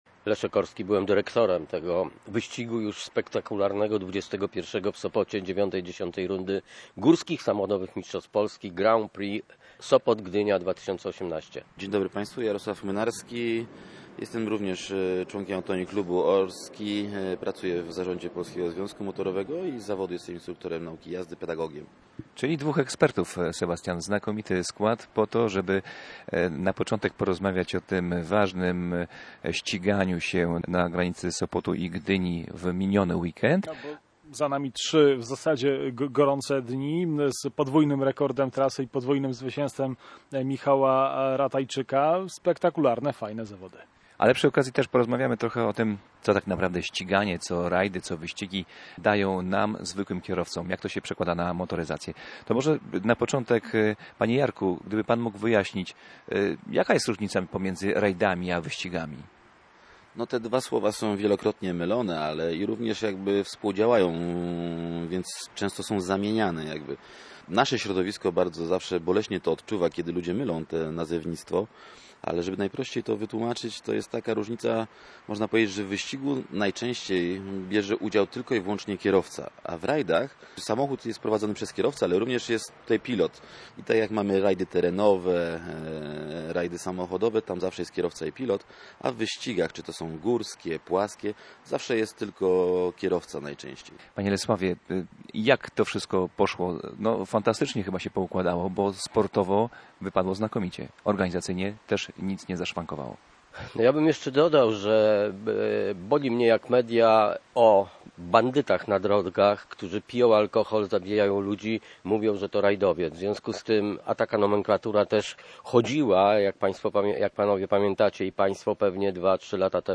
W motoryzacyjnej audycji specjaliści od rajdów